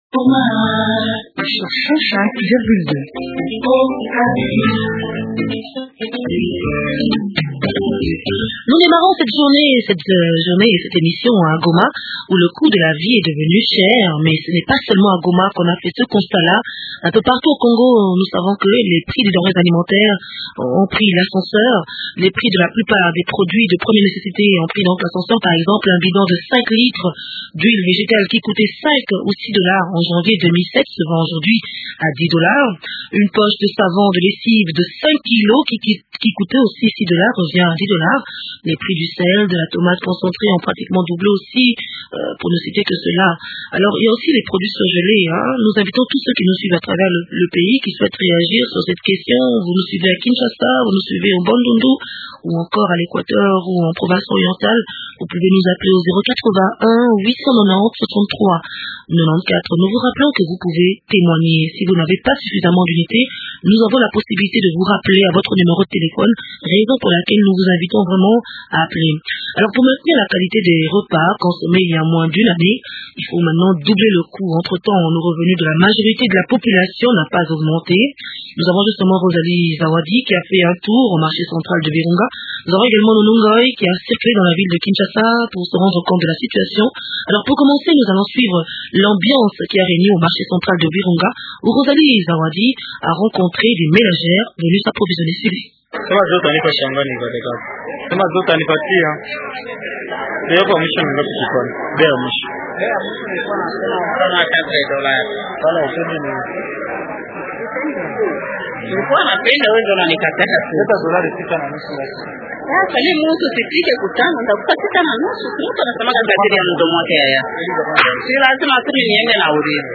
a fait un tour au marché central de Virunga où elle a rencontré des ménagères venues s’approvisionner.